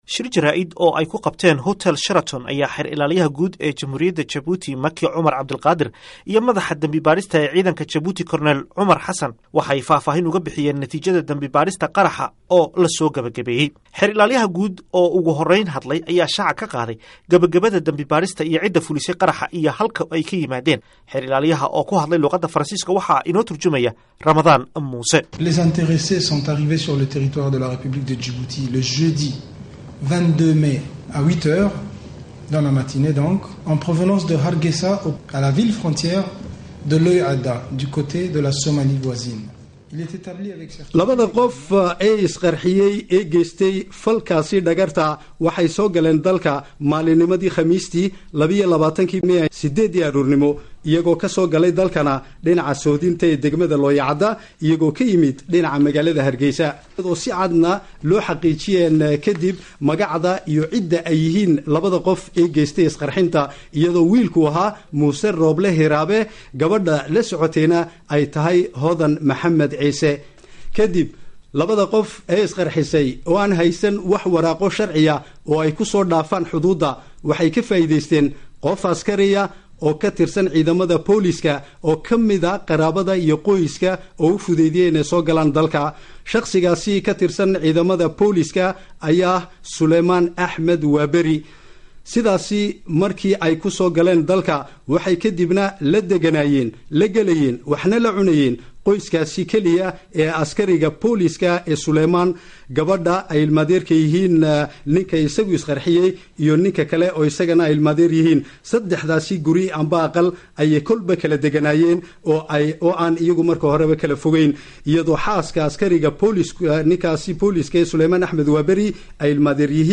Warbixinta Qaraxa Djibouti